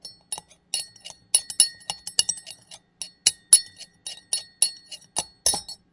勺子在杯子里
描述：一个茶匙在一个空的瓷咖啡杯里搅动着。在工作中用一个功能尚可的SM58录音（有人在某个时候弄破了外壳别问我怎么弄的......）
Tag: 现场记录 勺子 搅拌